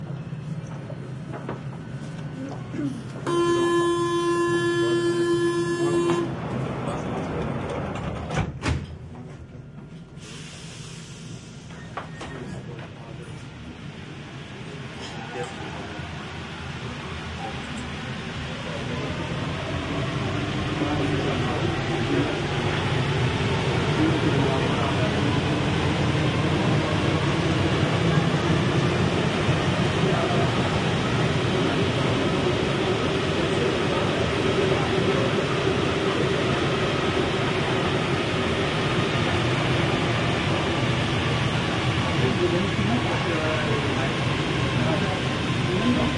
地铁站序列
描述：这是法国巴黎5号线地铁列车的通常启动程序。它包括一个启动蜂鸣器、关门装置的声音、一阵压缩空气的释放（可能与休息有关......）和列车离开的声音。在X/Y立体声模式下，用变焦h2n从站台上录制。
Tag: 地铁 地下 巴黎地铁 地铁 现场录音